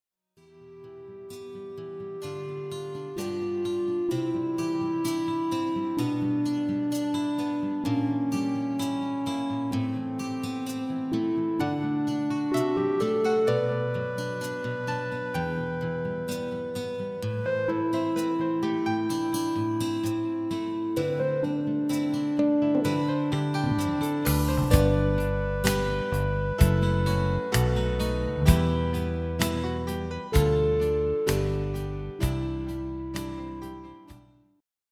Genre: Country & Western
- GM = General Midi level 1
- Géén vocal harmony tracks
Demo = Demo midifile